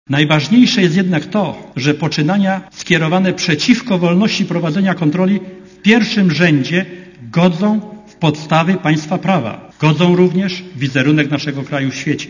zagrożona - mówił w piątek wieczorem w Sejmie prezes NIK Mirosław
Mówi Mirosław Sekuła (48Kb)
sekulasejm.mp3